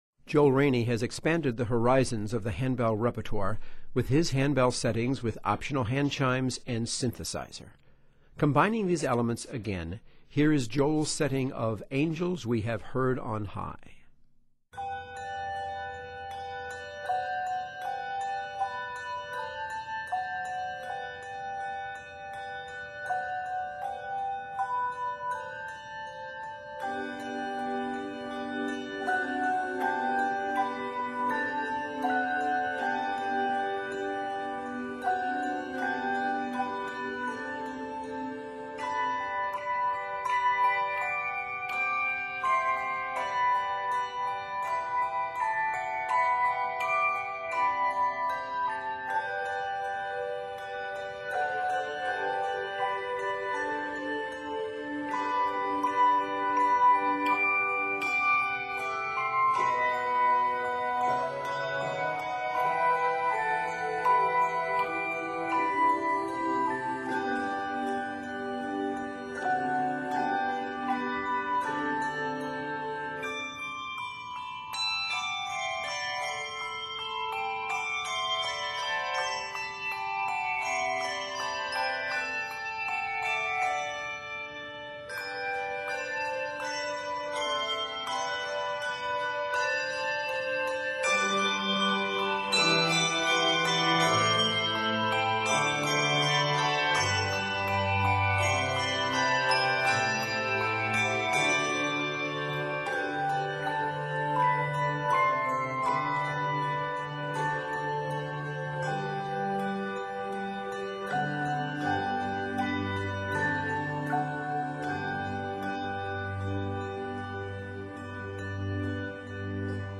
with optional choirchimes and a synthesizer part